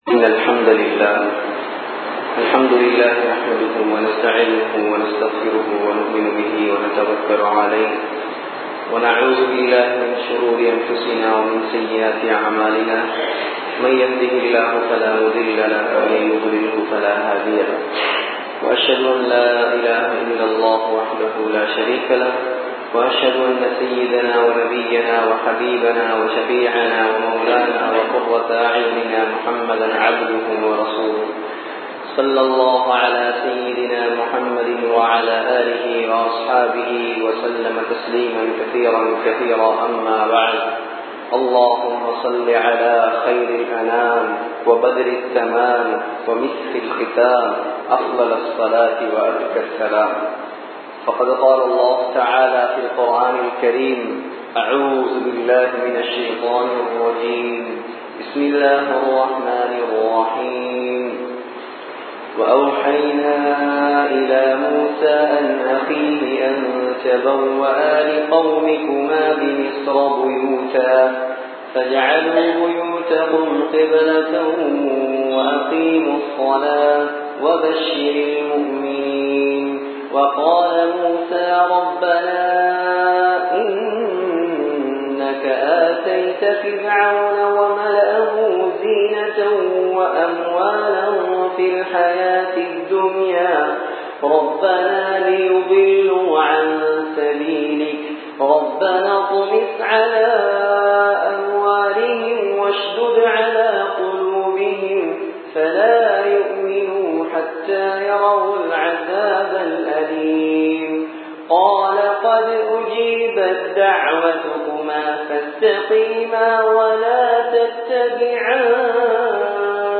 துன்யாவின் மோகம் வேண்டாம் | Audio Bayans | All Ceylon Muslim Youth Community | Addalaichenai